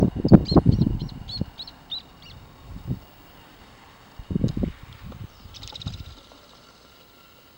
Spot-breasted Thornbird (Phacellodomus maculipectus)
Location or protected area: El Infiernillo
Condition: Wild
Certainty: Recorded vocal